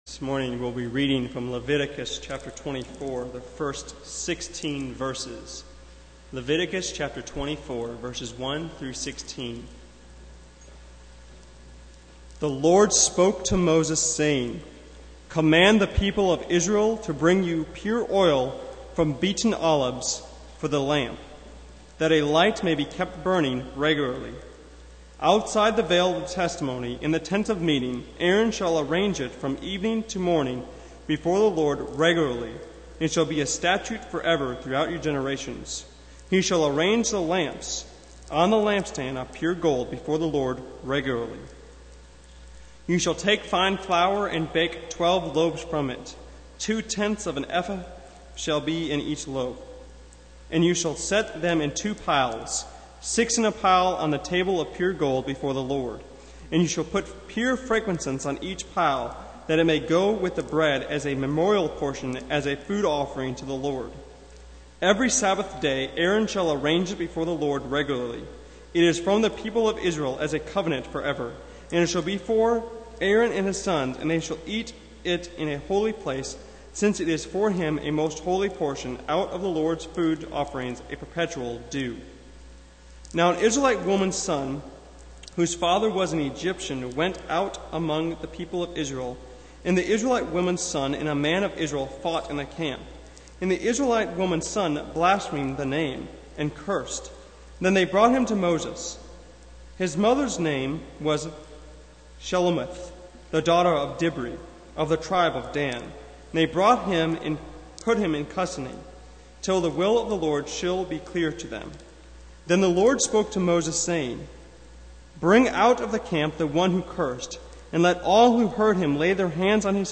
Passage: Leviticus 24:1-23 Service Type: Sunday Morning